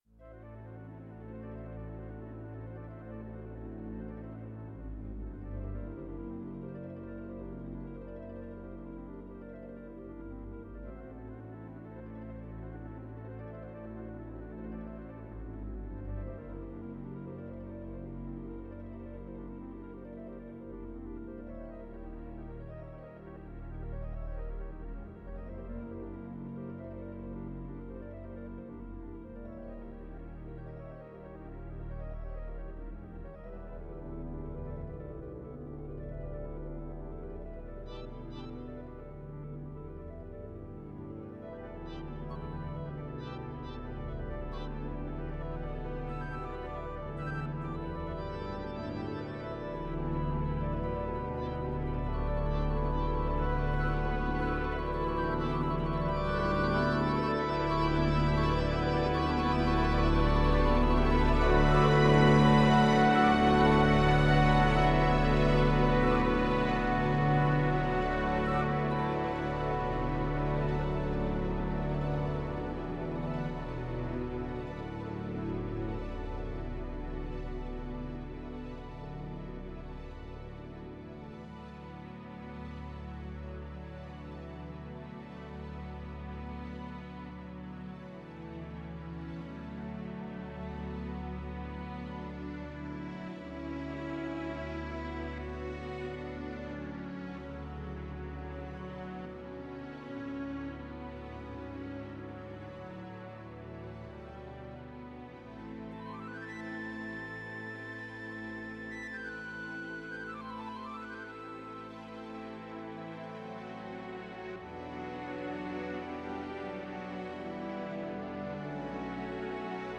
This is a MIDI cover of an excerpt of "Daphnis et Chloé," a piece by Ravel.